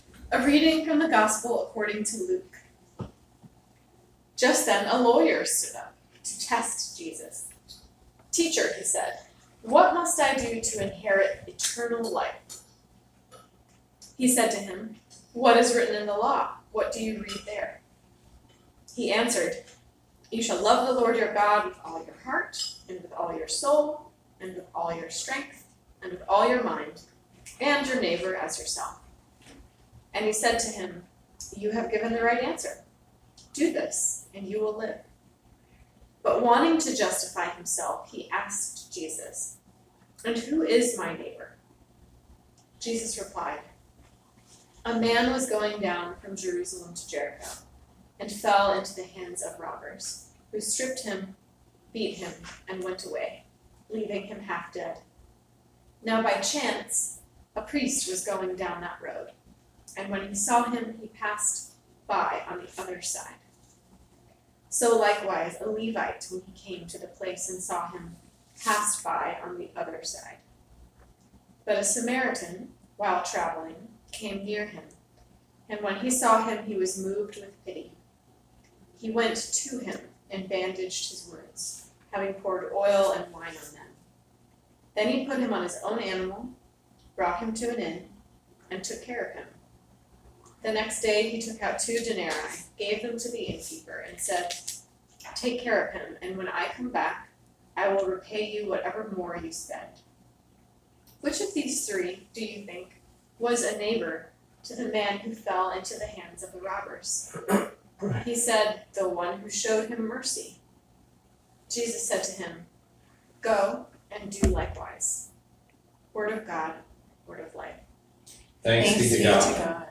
July 15, 2019 Sermon